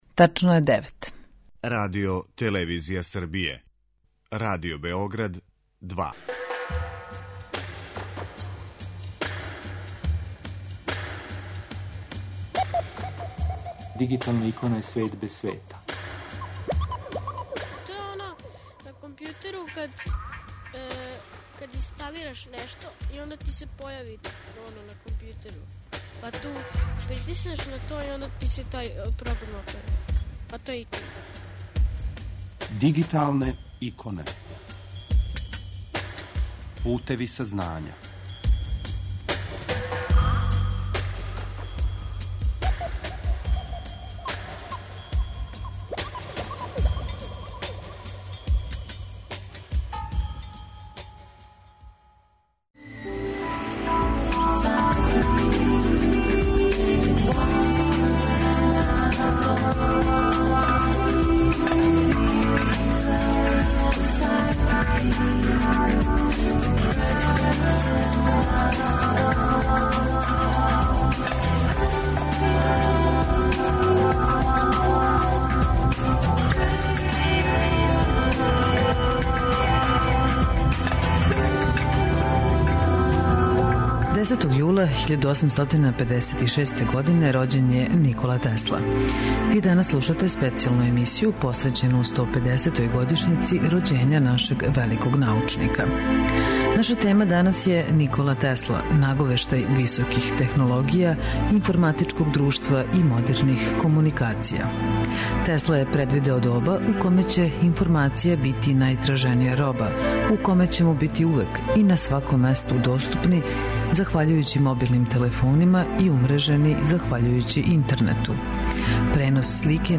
Данас слушамо специјално издање емисије снимљене и емитоване 2006. године поводом обележавања јубилеја - 150 година од рођења Николе Тесле.